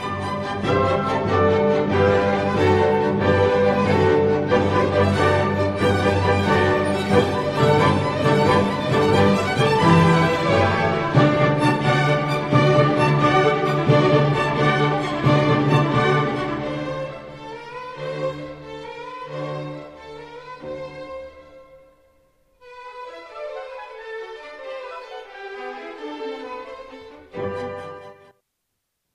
در سمفونی “سوپرایز” با آکُردهای ناگهانی بلندی موجه می شویم همچنین در کوارتتها و تریوهای او این ریتم طنز دیده می شود، زیرا بیشتر موسیقی او به منظور خوش آمد پرنس ساخته می شد!